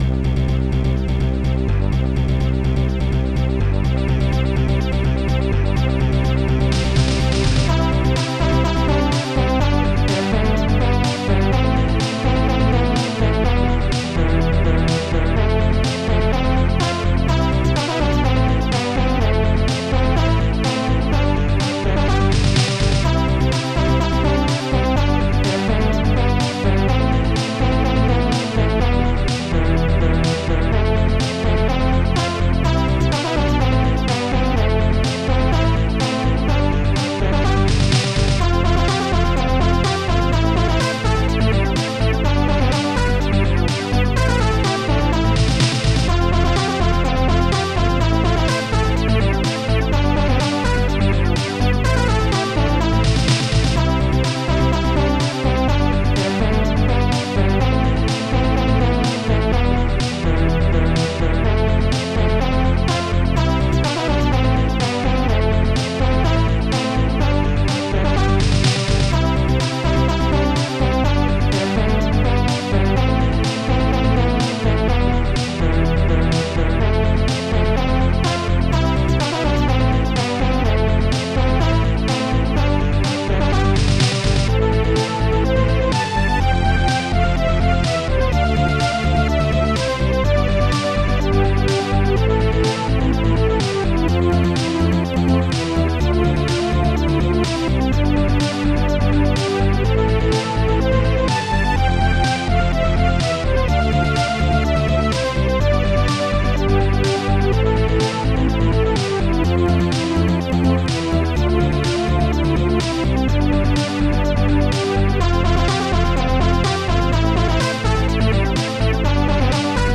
st-02:sqbrass st-01:slapbass st-01:popsnare2 st-01:korgbeau st-01:strings4 st-01:strings3 st-02:starpeace